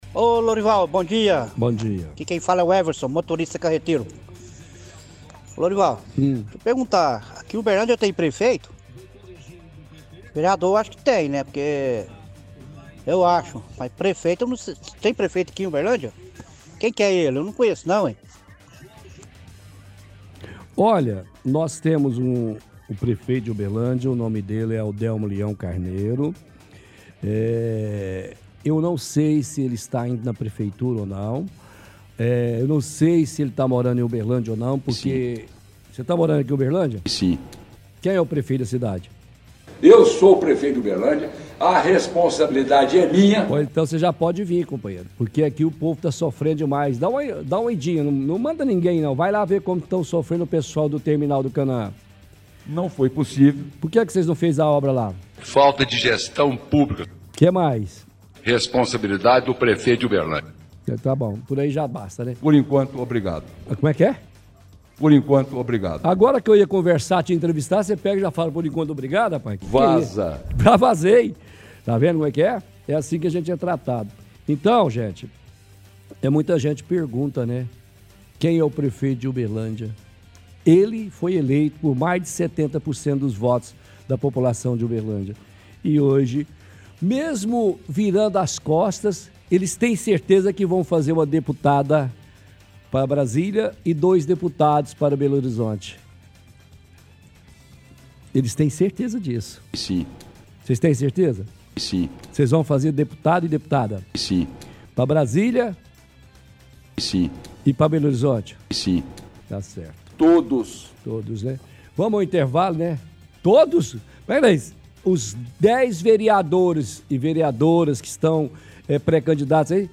– Ouvinte questiona se Uberlândia tem prefeito, pois ele não conhece.
– Piadas utilizando áudios do prefeito.